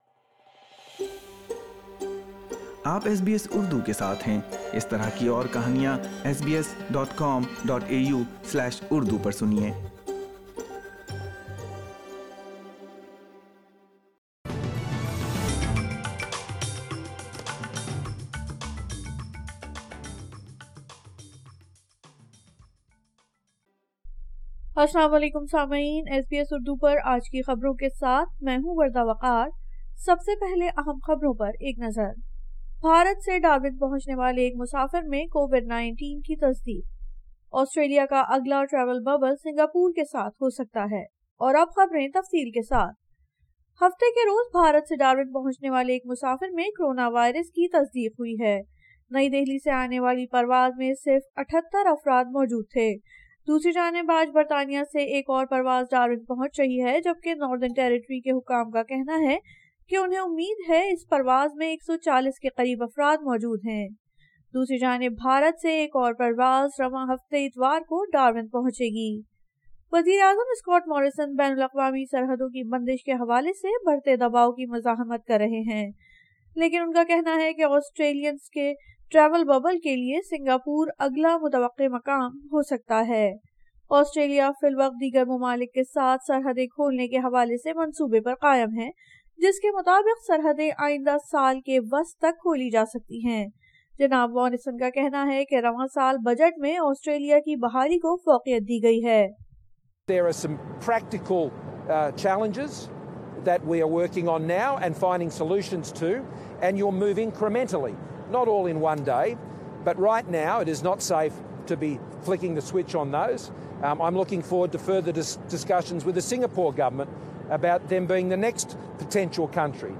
SBS Urdu News 17 May 2021
An Australian repatriated from India tests positive for COVID-19 in Darwin, Singapore may be the next possible location for Australia's travel bubble. Catch the latest Urdu news.